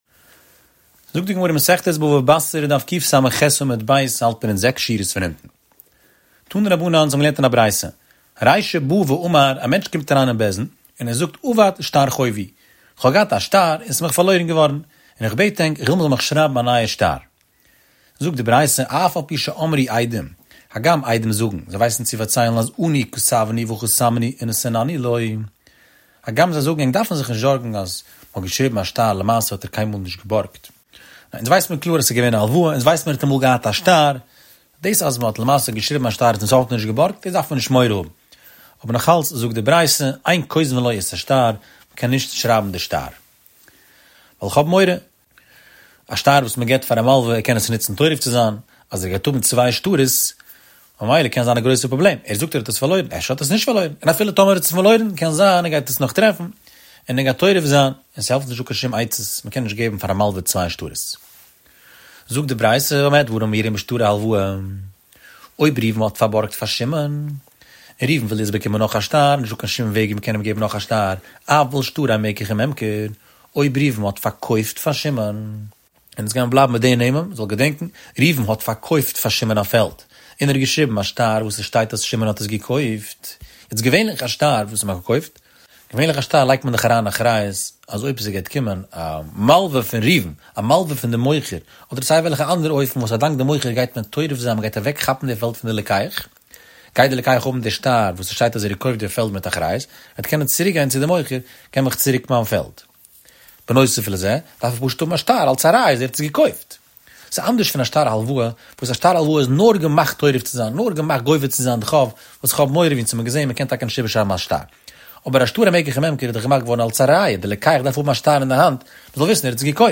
Daf Shiur